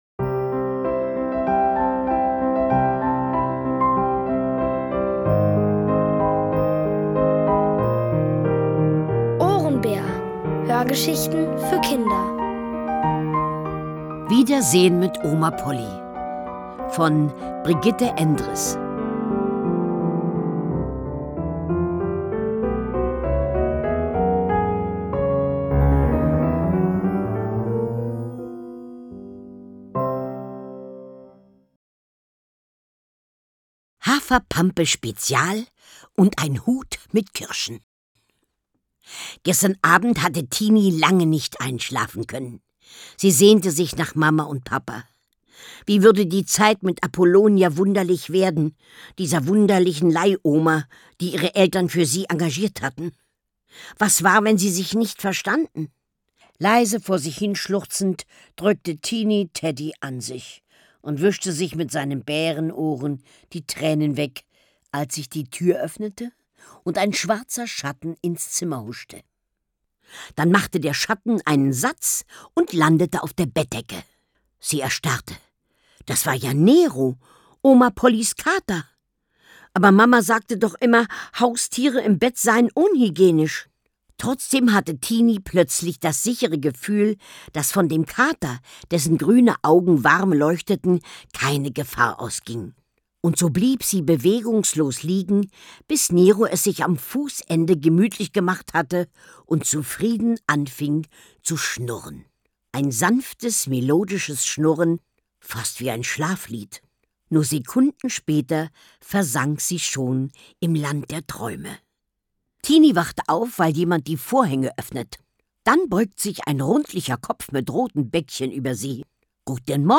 Von Autoren extra für die Reihe geschrieben und von bekannten Schauspielern gelesen.
Lukas und die Unendlichkeit | Die komplette Hörgeschichte!